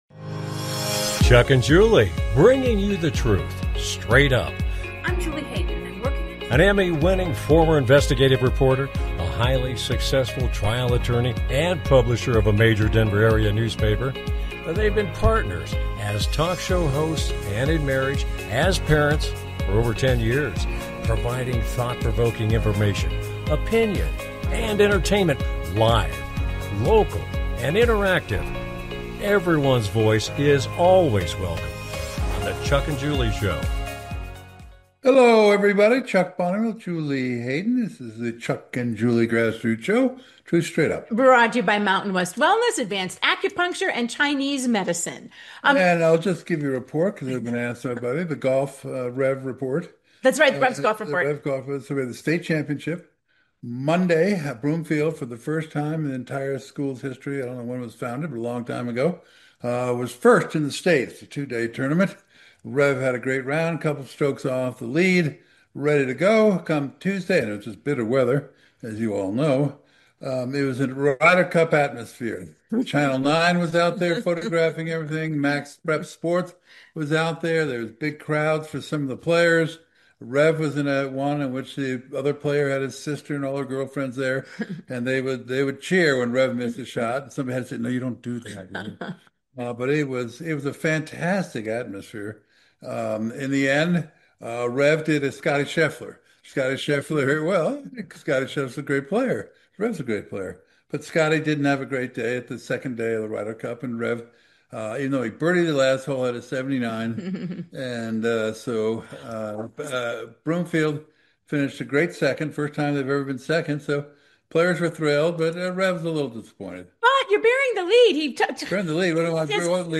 Talk Show Episode, Audio Podcast
Their program is a live Internet call-in talk show providing thought provoking information, conversation and entertainment.